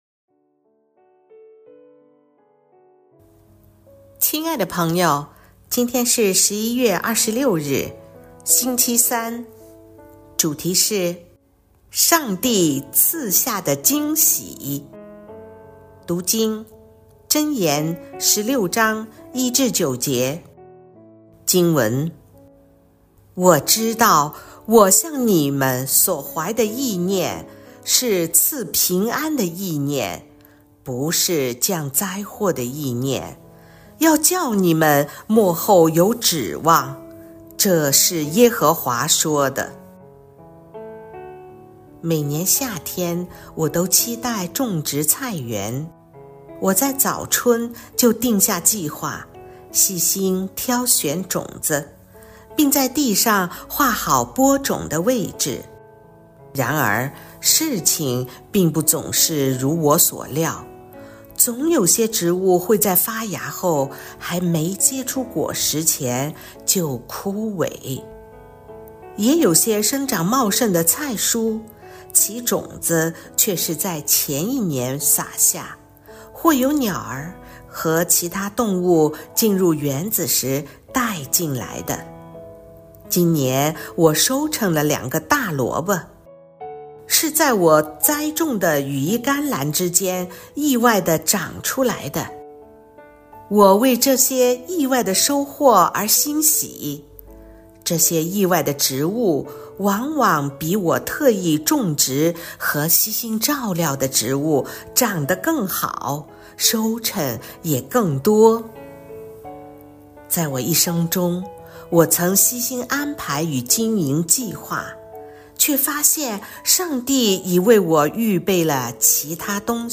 循道衞理聯合教會香港堂 · 錄音佈道組 Methodist Outreach Programme
錄音員